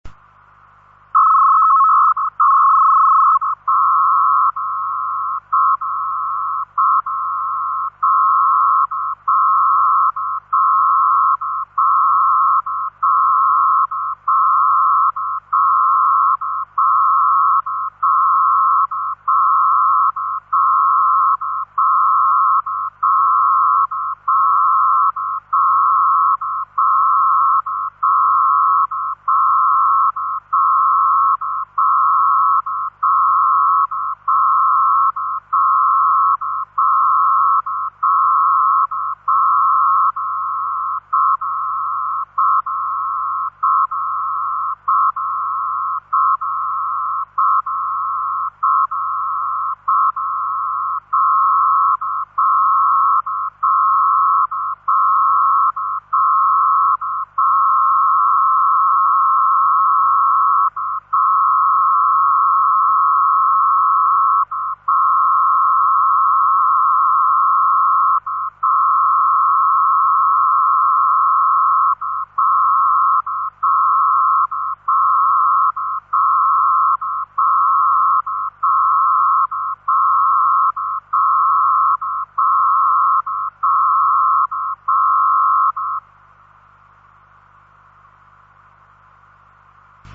MODI DIGITALI
Il suono di PACTOR II
Il suono di PACTOR II.mp3